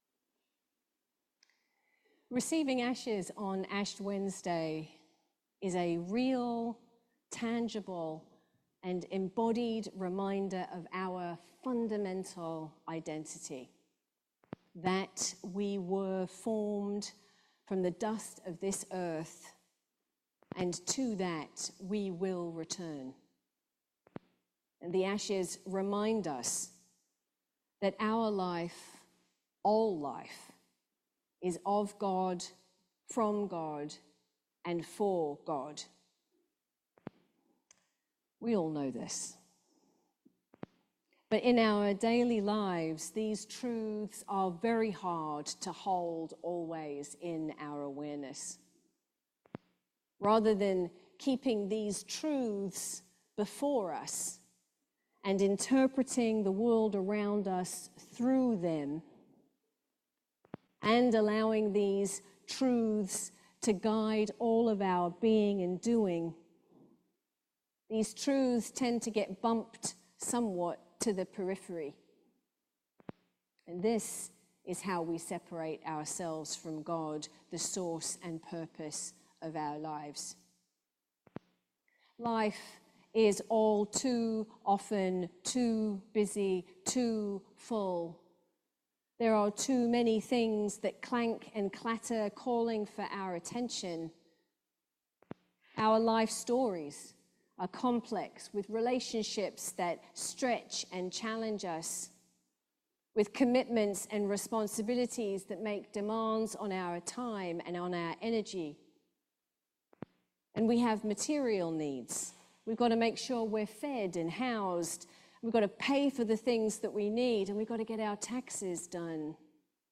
Sermon for Ash Wednesday